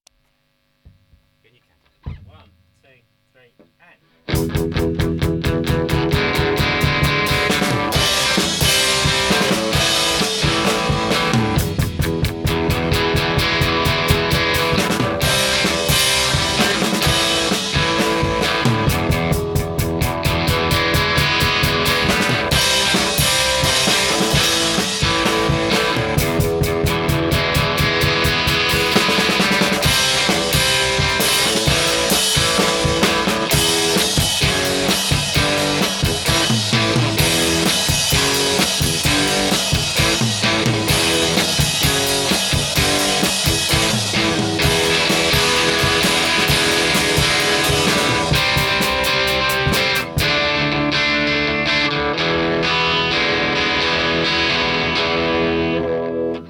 It’s a punky number and I literally learned the chords during the warm up that morning